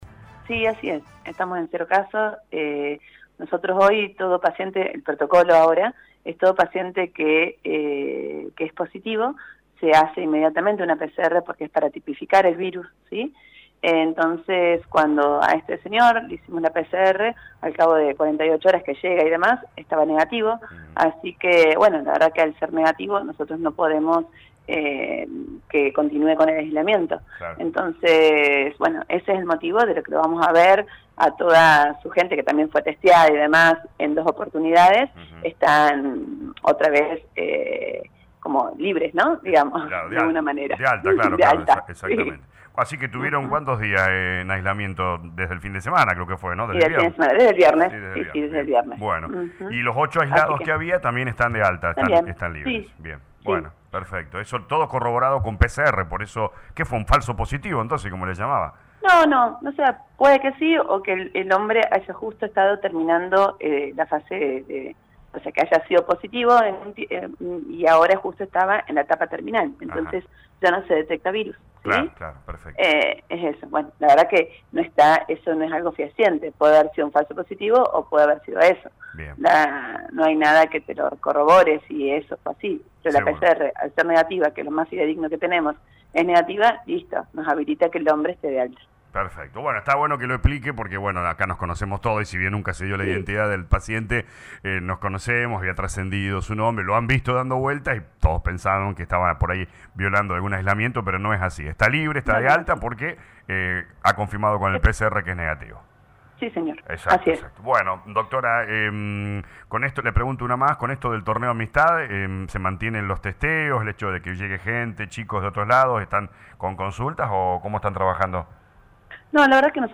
en dialogo con LA RADIO 102.9